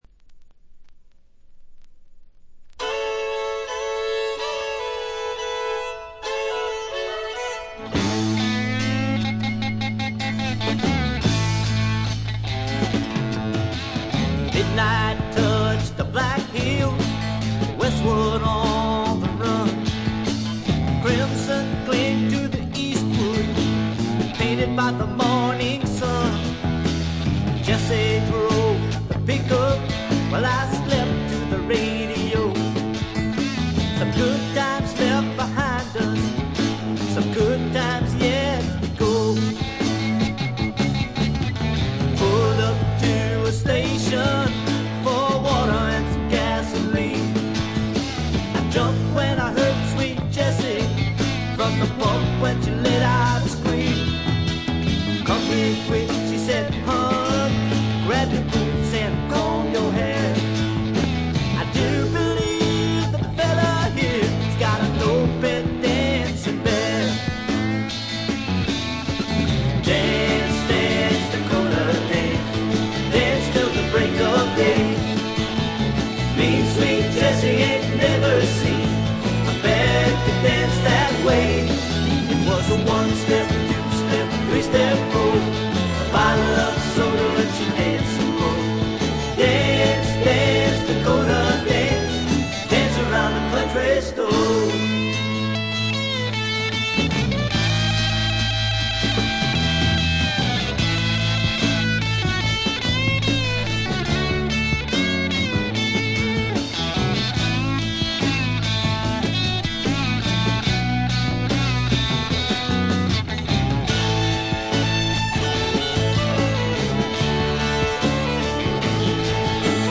ホーム > レコード：カントリーロック
B2前半でプツ音。
試聴曲は現品からの取り込み音源です。